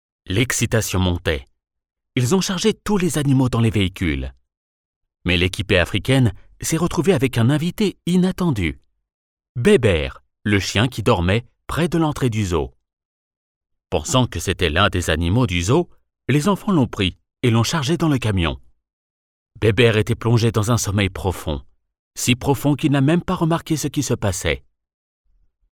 locutor, French voice over